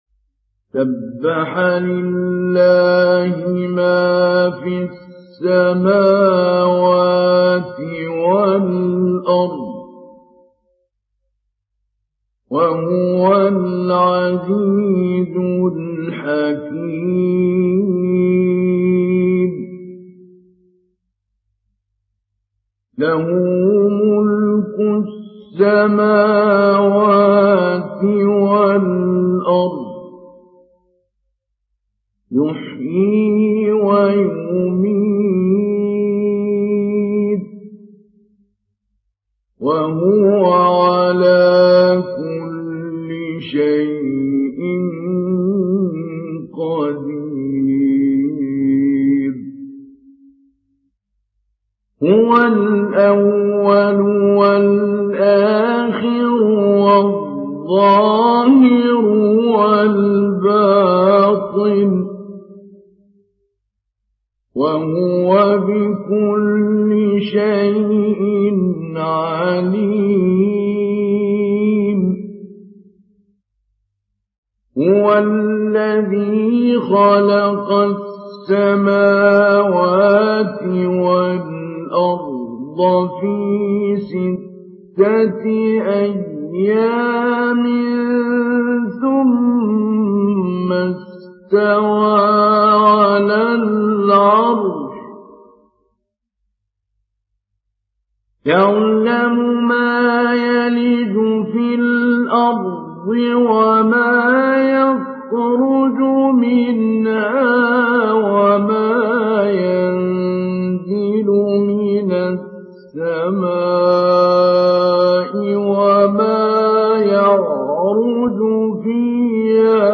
Surah Al Hadid Download mp3 Mahmoud Ali Albanna Mujawwad Riwayat Hafs from Asim, Download Quran and listen mp3 full direct links
Download Surah Al Hadid Mahmoud Ali Albanna Mujawwad